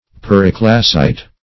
Search Result for " periclasite" : The Collaborative International Dictionary of English v.0.48: Periclase \Per"i*clase\, Periclasite \Per`i*cla"site\, n. [Pref. peri- + Gr.